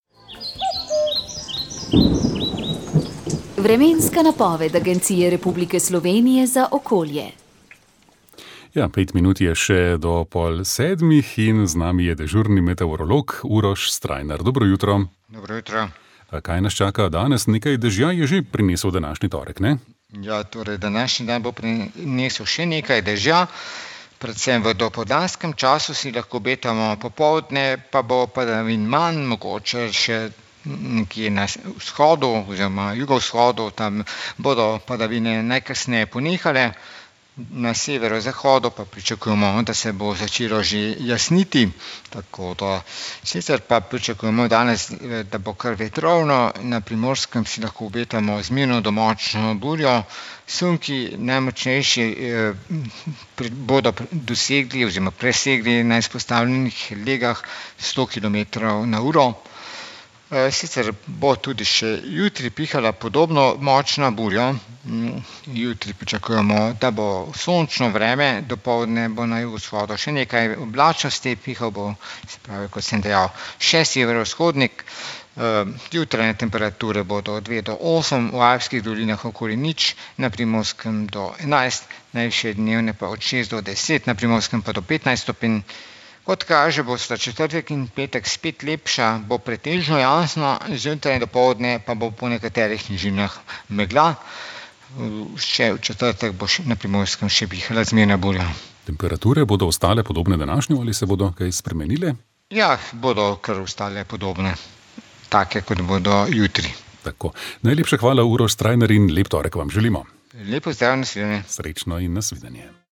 Vremenska napoved 23. november 2022